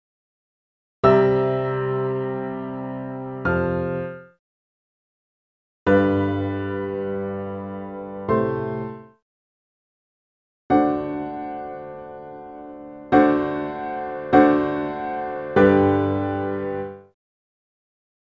One of the most famous series of dominant sevenths is this…
… the unsettling, key-shifting beginning of Beethoven’s First Symphony.